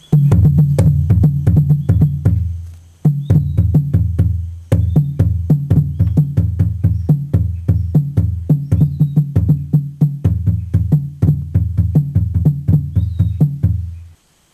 Каждый барабан издаёт по два звука разной высоты: барабан побольше отвечает за более низкие звуки, а барабан поменьше — за более высокие.
Барабаны подвешиваются на специальный каркас, и барабанщик стоит между ними, ударяя по деревянным корпусам колотушками с резиновым покрытием (рис. 2).
Речевой модус задействует только два звука, по одному от каждого барабана — по количеству тонов в языке бора.
sound_drums.mp3